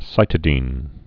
(sītĭ-dēn)